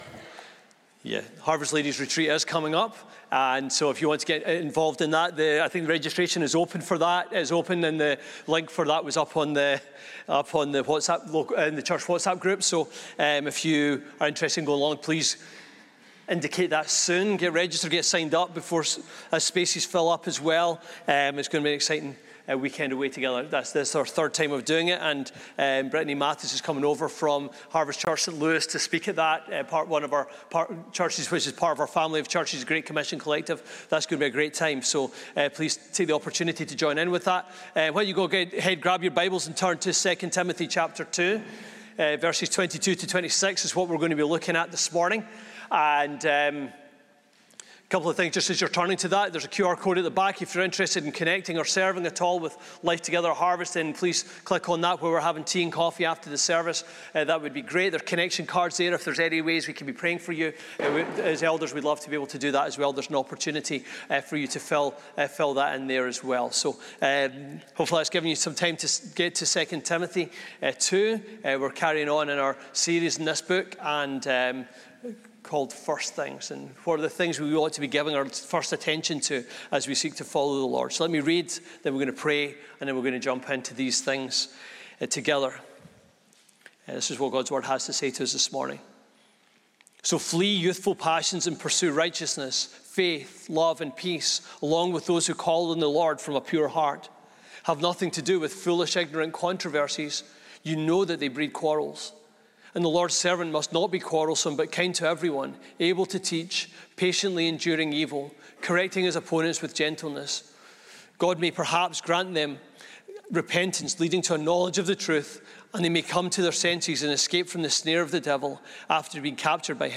This sermon is also available to watch on YouTube.